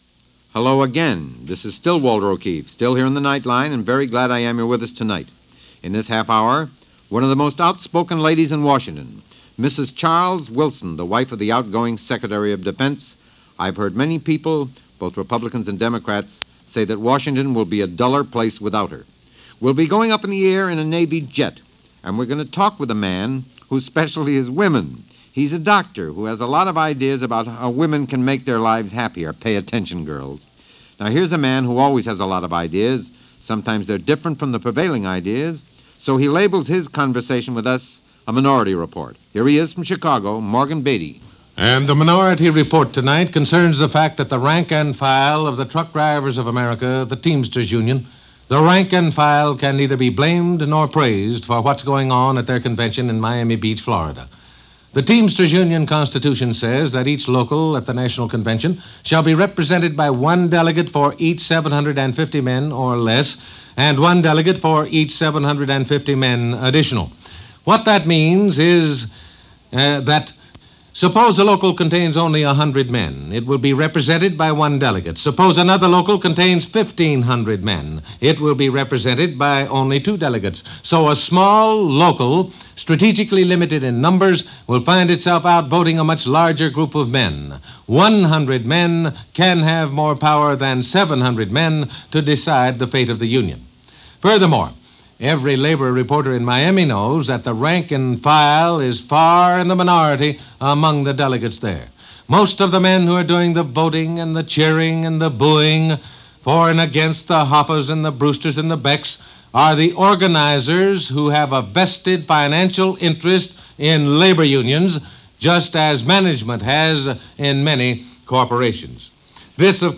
Day In The Life - October 2, 1957 - NBC Radio's five-night-a-week program Nightline, hosted by Walter O'Keefe.
An interview with one of the members of the Blue Angels Navy Flying Team, discussing flying in formation and the level of skill it takes to pilot a Jet to do that.
The announcer explains that more people are enjoying material comfort in this time (October 1957) than at any other time in history.